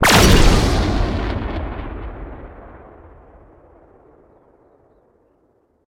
ships / combat / weapons